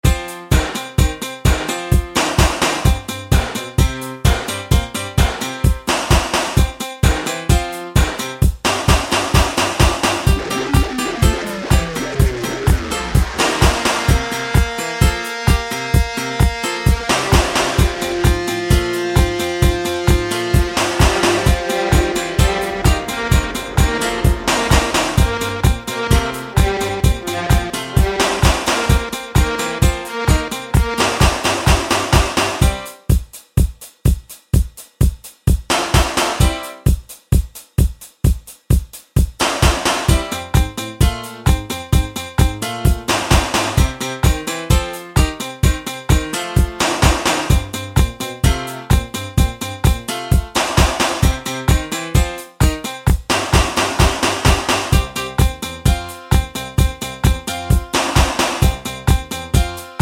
no Backing Vocals Punk 2:31 Buy £1.50